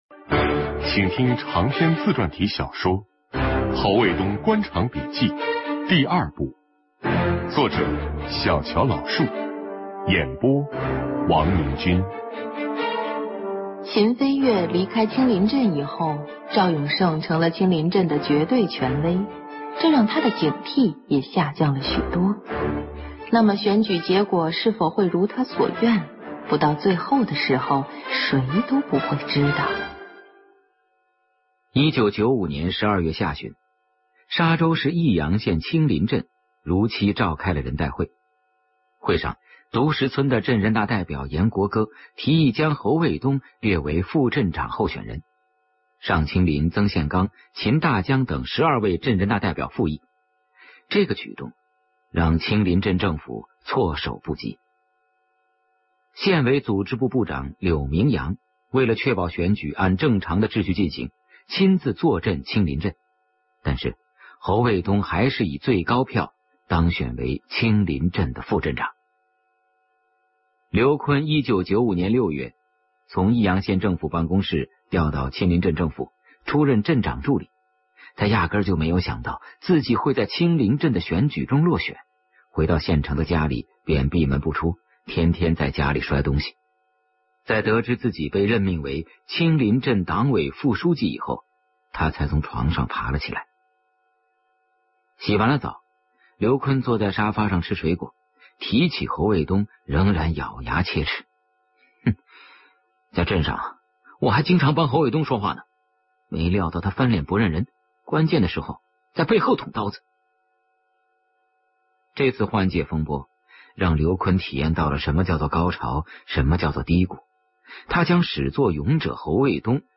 【有声文学】《侯卫东官场笔记2》